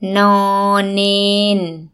– noor – neen
noor-neen.mp3